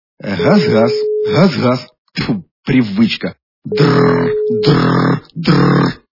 » Звуки » Смешные » Говорящий телефон - раз, раз, ой дзын-дзын...
При прослушивании Говорящий телефон - раз, раз, ой дзын-дзын... качество понижено и присутствуют гудки.
Звук Говорящий телефон - раз, раз, ой дзын-дзын...